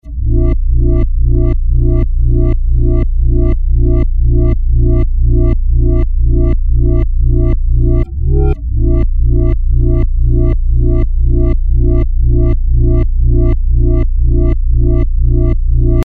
描述：另一个来自流氓莫格的肮脏低音波
标签： 模拟 低音 穆格 合成器 音调
声道立体声